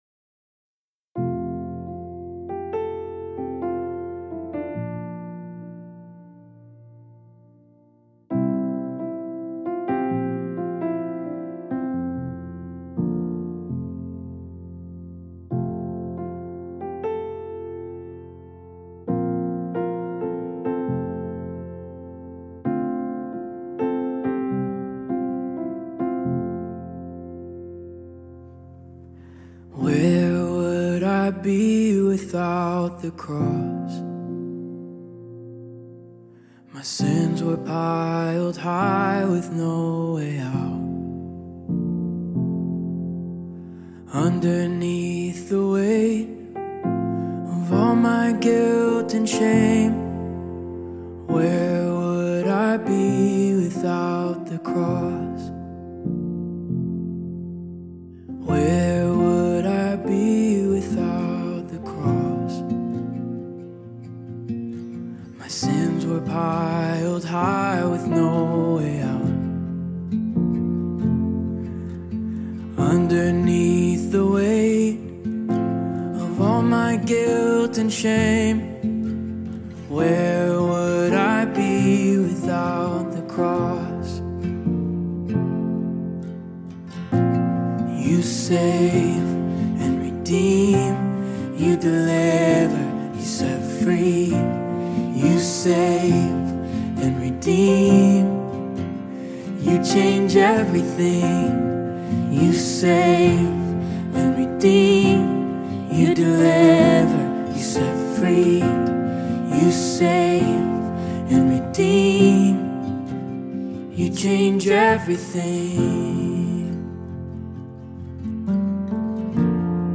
BPM: 69